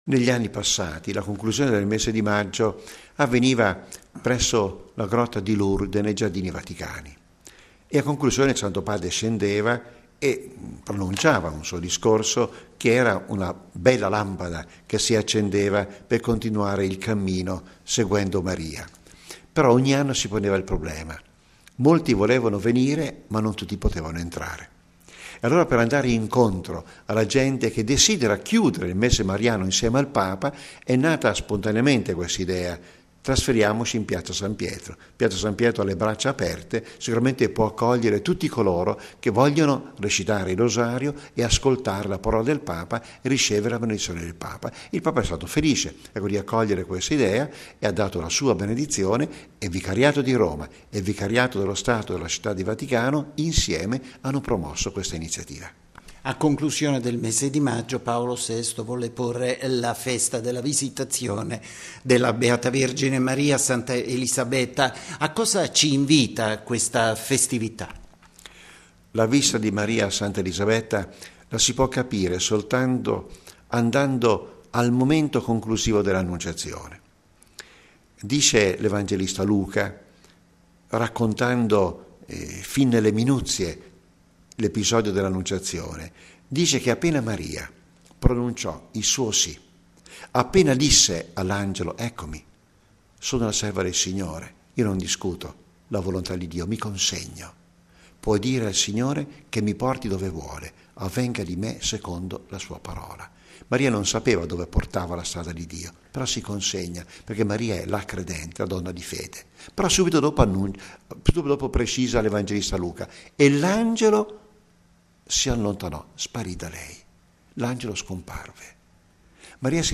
Questa sera in Piazza San Pietro la chiusura del mese mariano presieduta dal Papa: intervista col cardinale Comastri